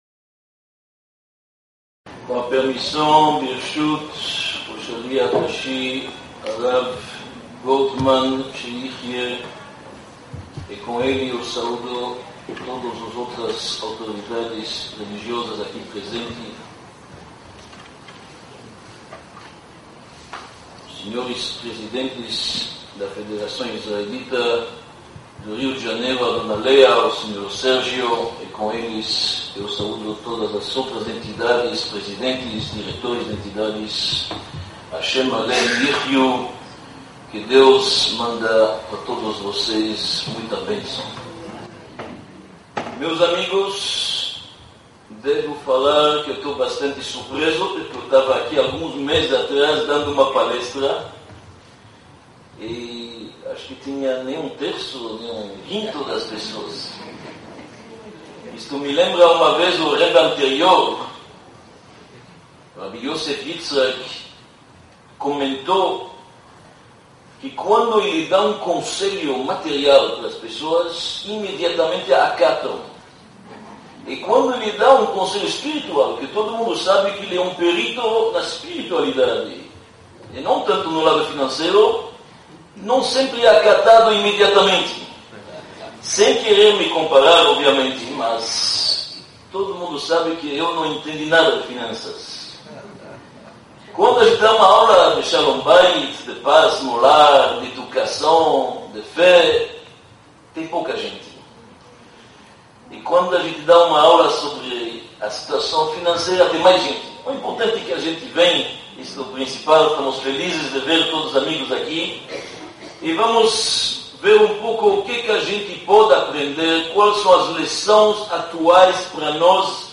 Palestra sobre crise financeira
Palestra-sobre-crise-financeira-1.mp3